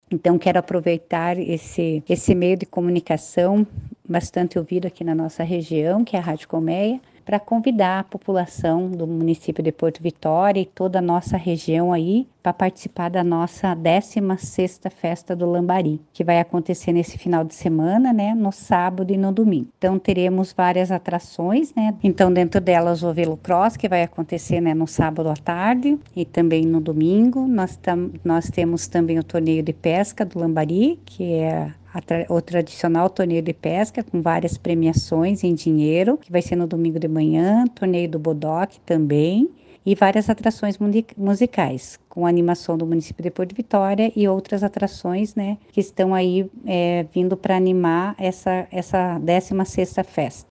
A 16º edição da Festa do Lambari de Porto Vitória está cada vez mais perto. A prefeita Marisa Ilkiu convida a população e fala sobre as atrações.